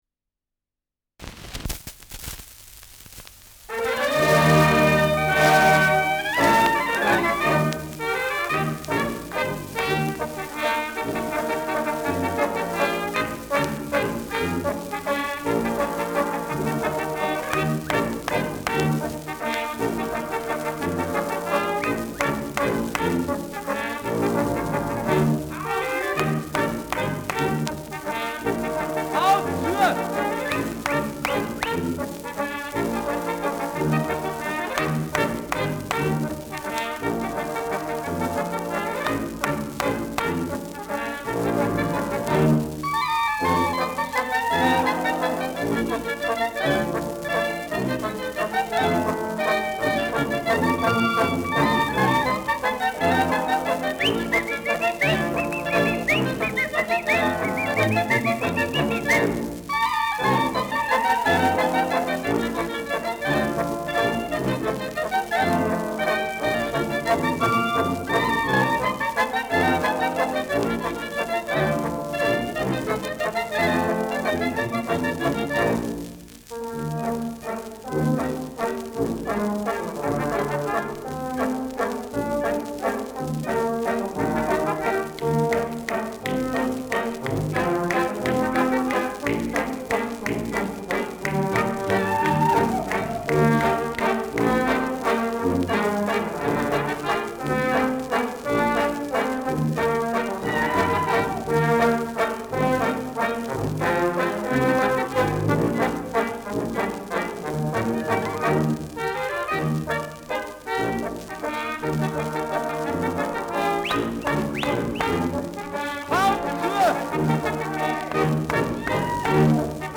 Schellackplatte
Leicht abgespielt : Gelegentliches Knistern : Gelegentliches Knacken : Leichtes Leiern : Schwingender Pfeifton am Schluss
Mit Klatschen und „Haut zua!“-Einwürfen.
[Berlin] (Aufnahmeort)